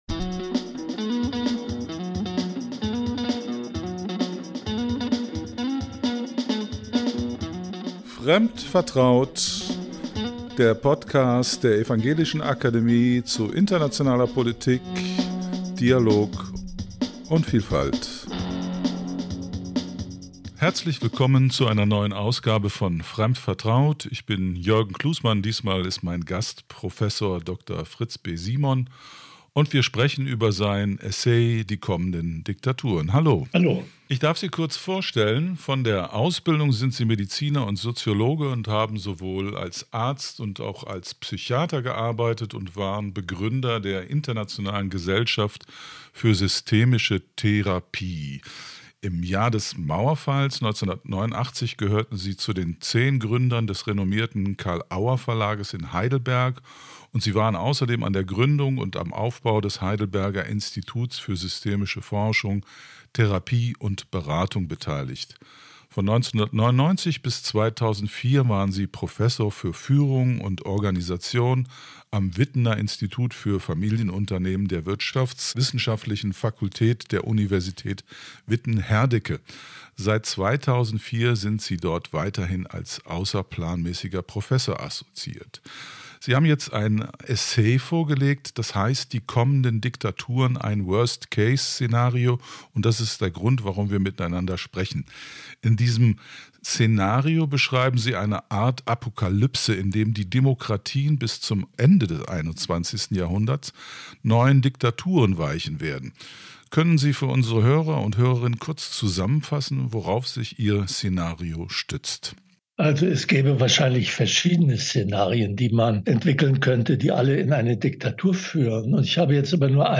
Bis zum Ende des Jahrhunderts rechnet er mit der Wiederkehr von Diktaturen. Das Gespräch wurde vor der Bundestagswahl aufgezeichnet.